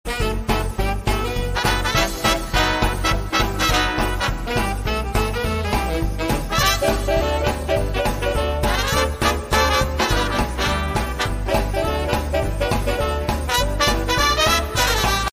A Night Of Disco Dancing Sound Effects Free Download